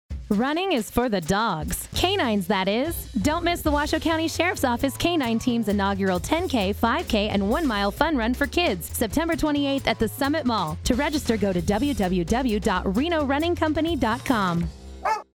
Media partners: radio public service announcements (:15 and :30 second) and event poster are attached.
Download this audio file - (15 second PSA - 603 KB mp3)